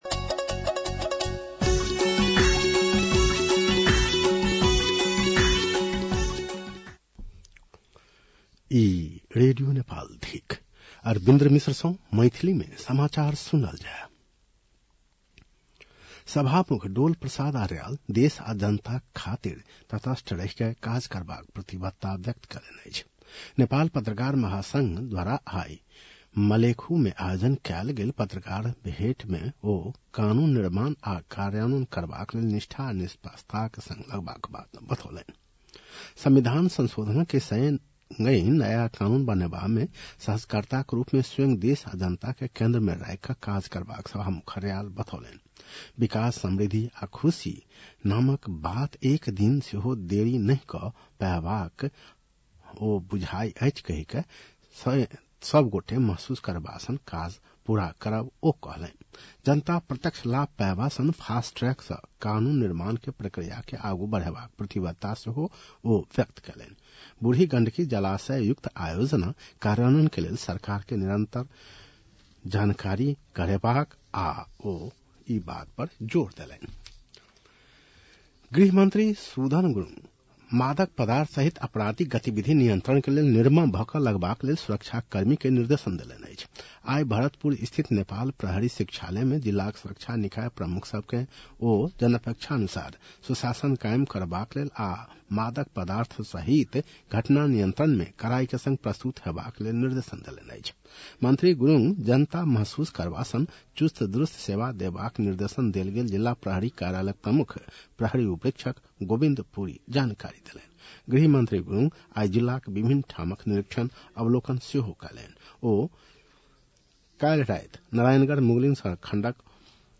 मैथिली भाषामा समाचार : २९ चैत , २०८२